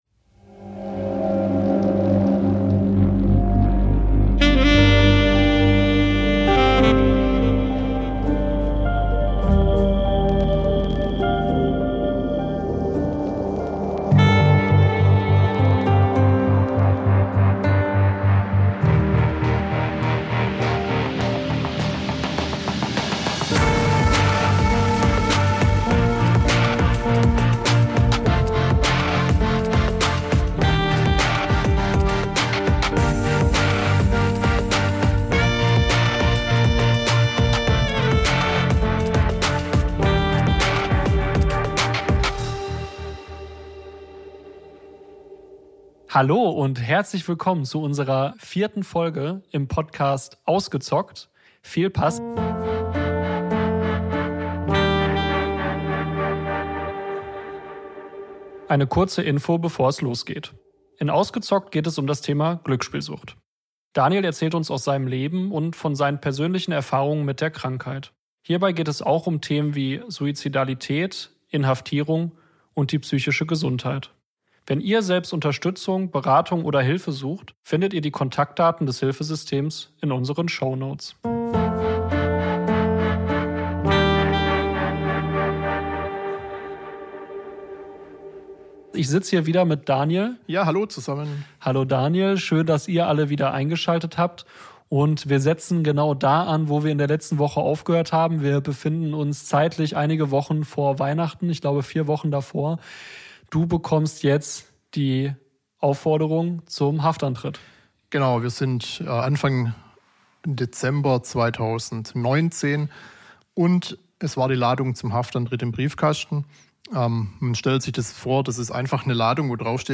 Folge 4: Hinter Gittern ~ Ausgezockt: Sucht ungefiltert - Betroffene im Gespräch Podcast